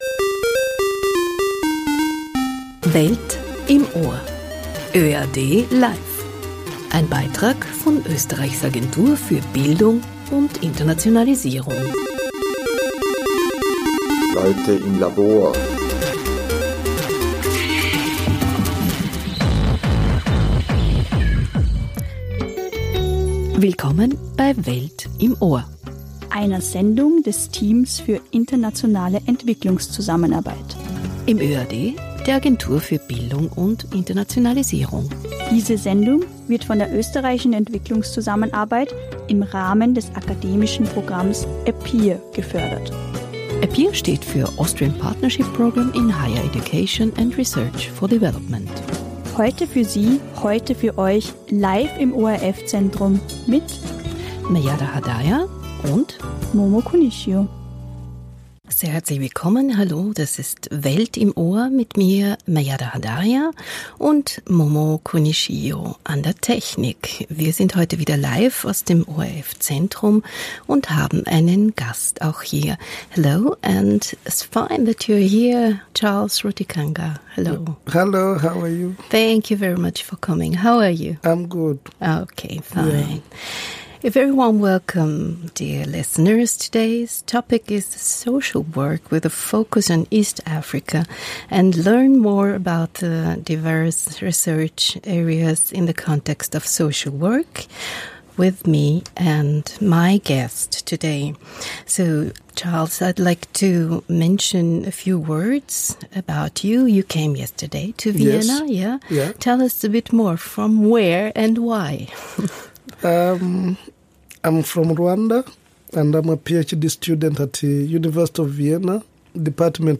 Welt im Ohr führt seine Hörer/innen in die Weiten entwicklungspolitischer Fragestellungen, hin zu Forschung und Bildung in den armen und ärmsten Regionen der Welt genauso wie in Österreich. Bei uns im Studio kommen engagierte Menschen zu Wort, die arbeiten und forschen, um gemeinsam die Welt ein wenig besser zu machen – partnerschaftlich in Bildung, Forschung und Entwicklung. Die Radiosendungen und Podcasts von "Welt im Ohr" vermitteln transkulturelle Erfahrungen aus Bildung, Wissenschaft und Forschung.